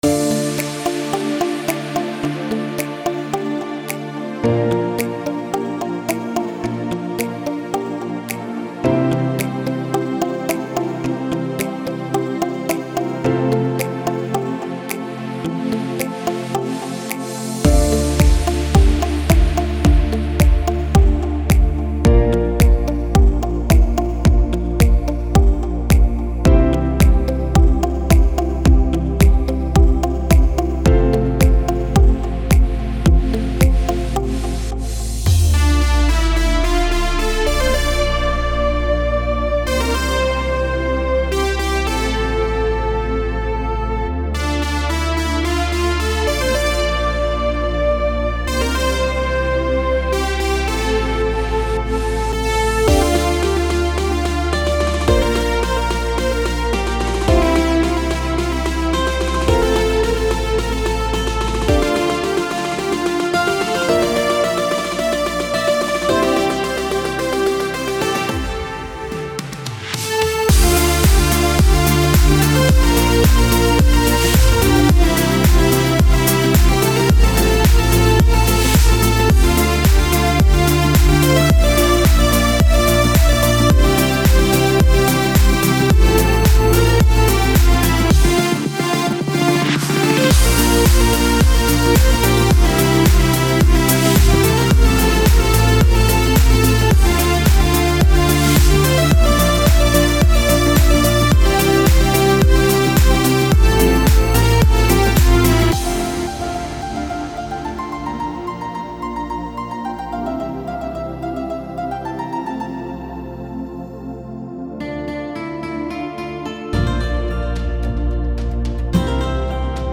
это инструментальная композиция